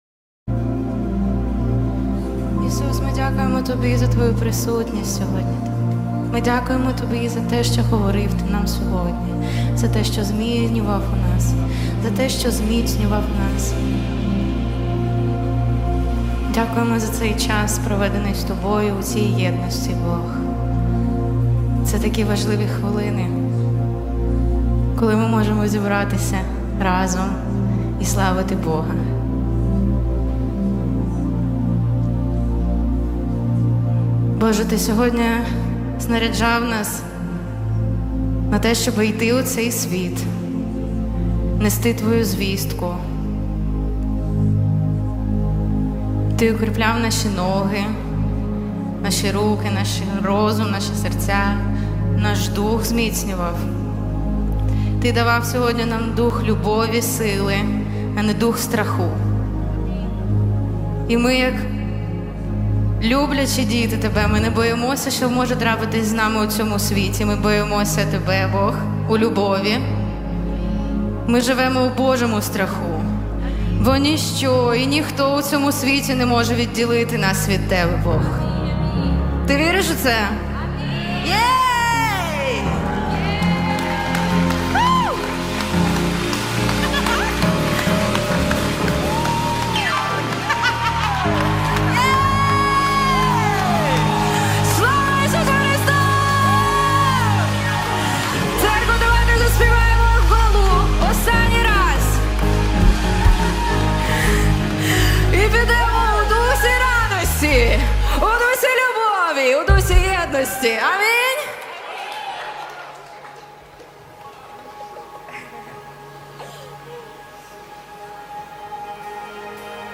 185 просмотров 78 прослушиваний 2 скачивания BPM: 67